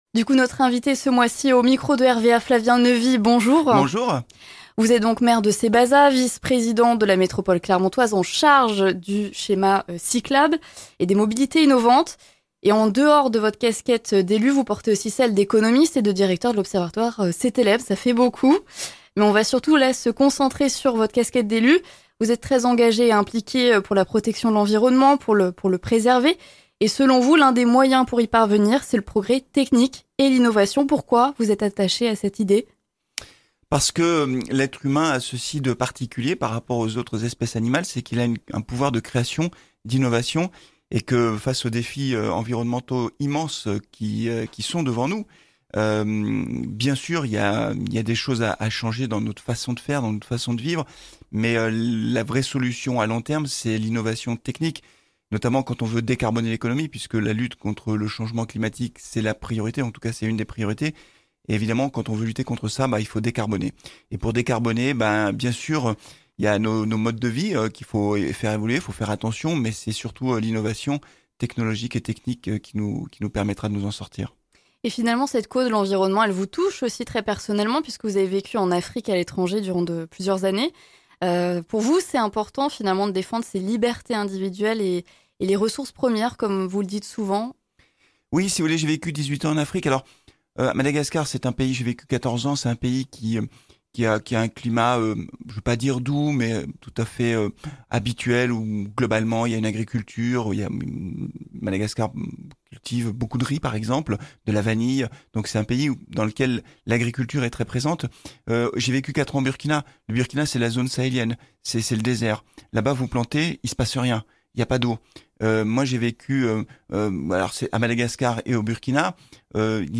Notre invité ce mois-ci, Flavien Neuvy, maire de Cébazat et vice-président de la métropole de Clermont en charge du schéma cyclable et des mobilités innovantes.
Notre invité au micro de RVA, Flavien Neuvy. On évoque avec lui la question des mobilités innovantes au sein de la métropole clermontoise.